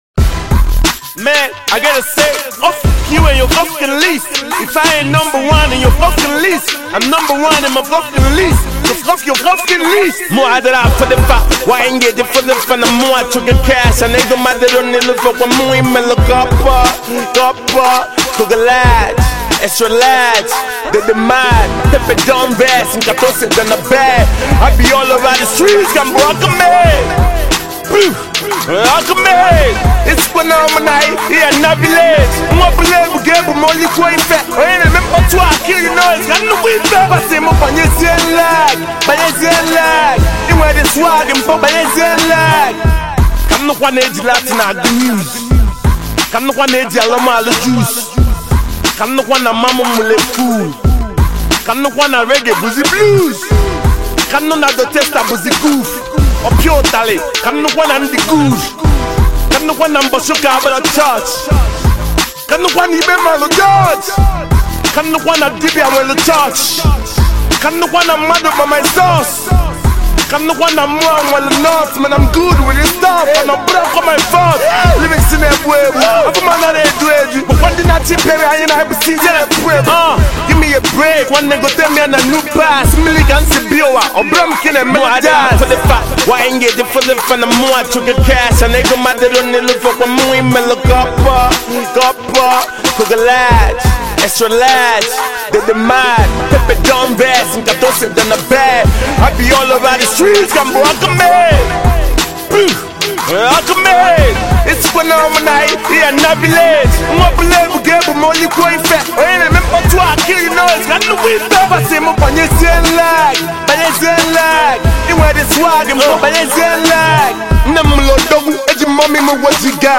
Blending smooth vocals with dynamic instrumentals